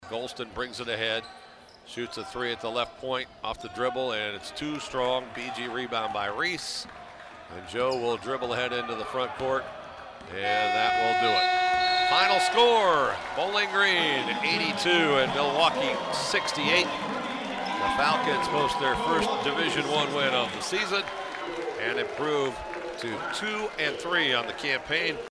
RADIO CALLS